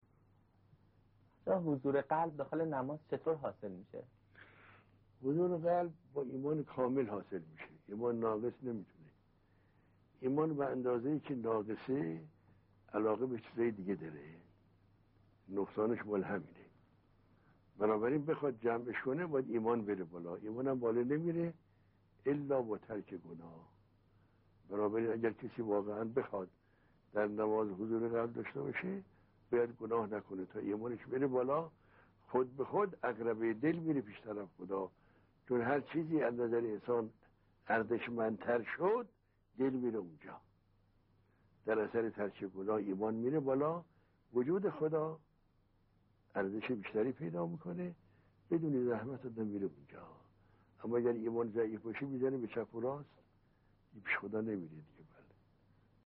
درس اخلاق | راز رسیدن به حضور قلب در نماز چیست؟ + صوت